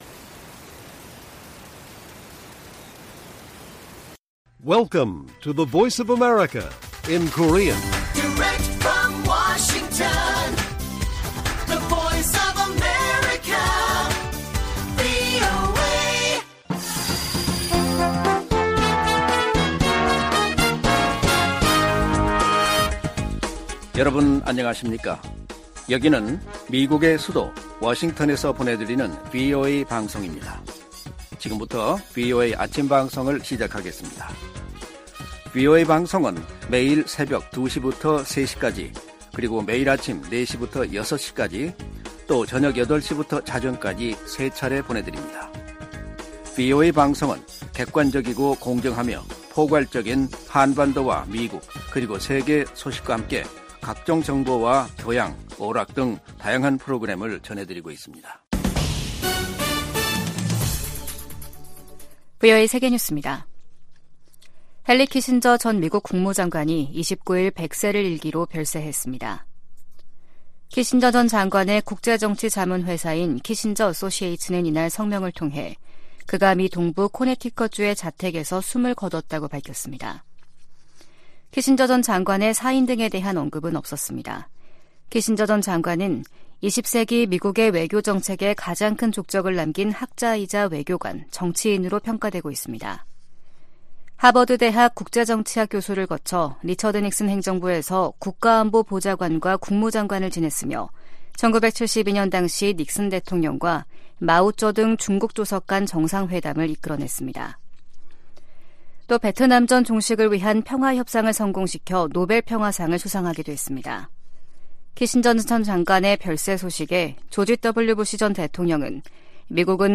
세계 뉴스와 함께 미국의 모든 것을 소개하는 '생방송 여기는 워싱턴입니다', 2023년 12월 1일 아침 방송입니다. '지구촌 오늘'에서는 제28차 유엔기후변화협약 당사국총회(COP28)가 아랍에미리트(UAE) 두바이에서 시작된 소식 전해드리고, '아메리카 나우'에서는 외교계 거목 헨리 키신저 전 국무장관이 100세를 일기로 별세한 이야기 살펴보겠습니다.